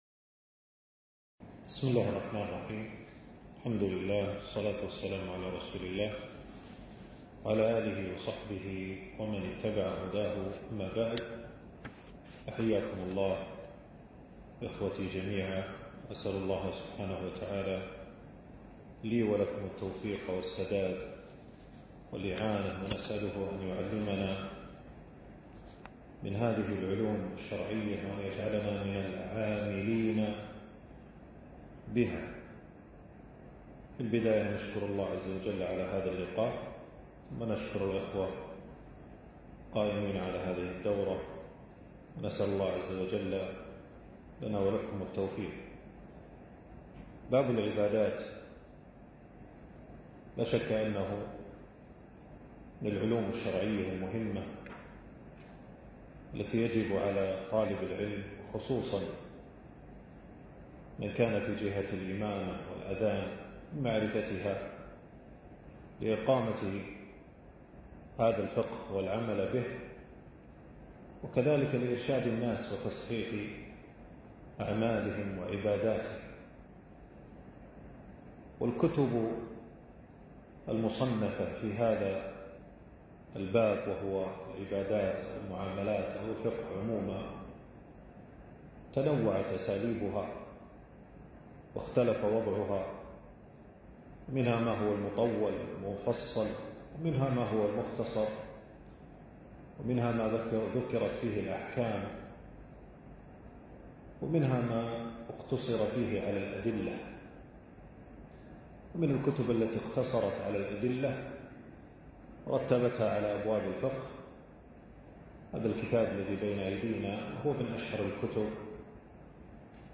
الدرس الاول
المكان: درس ألقاه في 2 جمادى الثاني 1447هـ في مبنى التدريب بوزارة الشؤون الإسلامية.